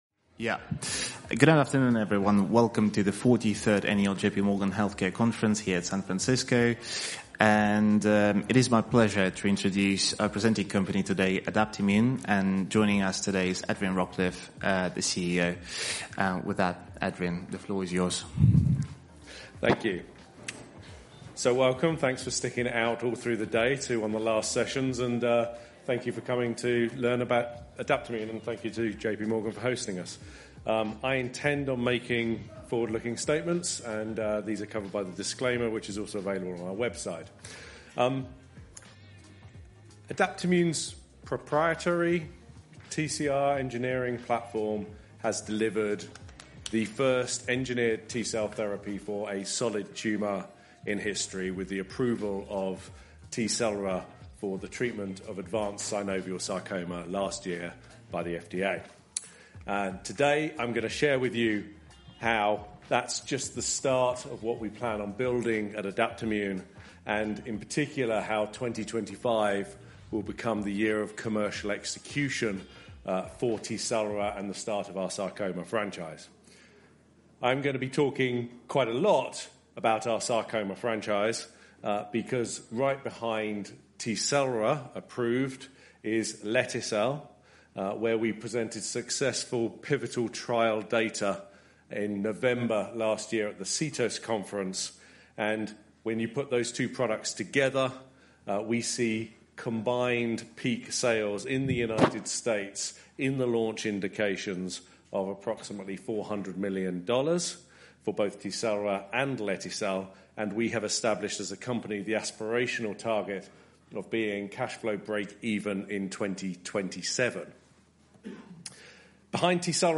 J.P. Morgan Healthcare Conference
company presentation